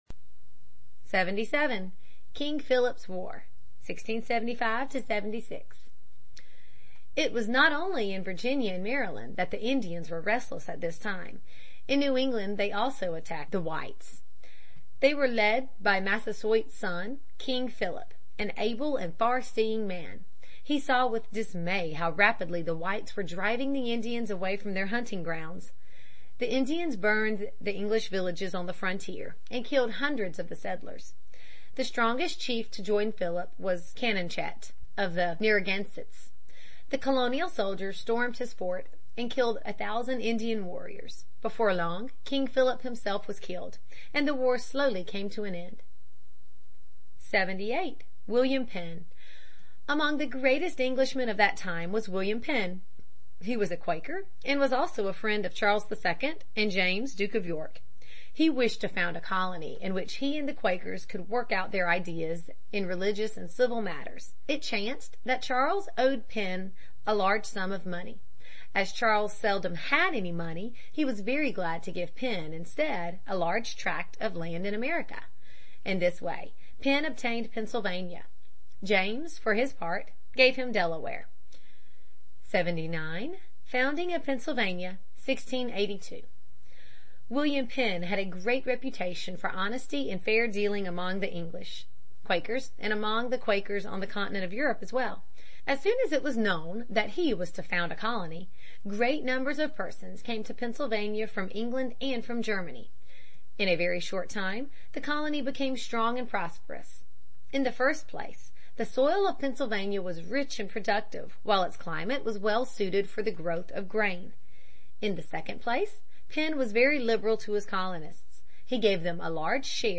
在线英语听力室美国学生历史 第25期:查理二世的殖民统治(4)的听力文件下载,这套书是一本很好的英语读本，采用双语形式，配合英文朗读，对提升英语水平一定更有帮助。